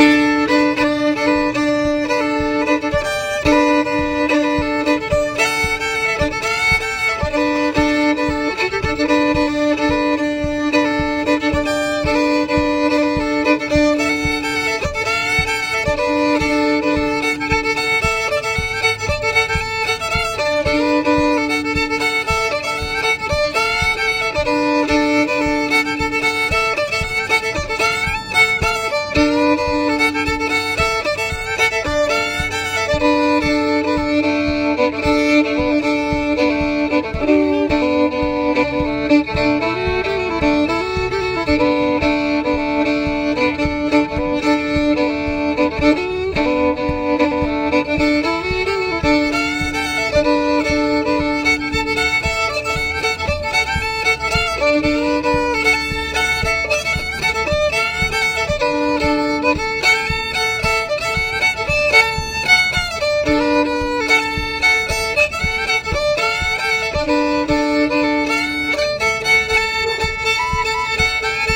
ANGELINE THE BAKER | MANDOLIN
Angeline the Baker Fiddle version (.mp3 file)